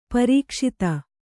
♪ parīkṣita